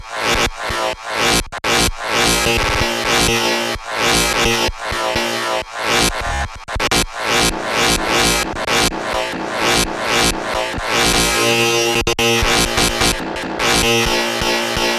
描述：劈头盖脸，我前段时间做的鼓。